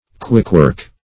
Search Result for " quickwork" : The Collaborative International Dictionary of English v.0.48: Quickwork \Quick"work`\, n. (Naut.)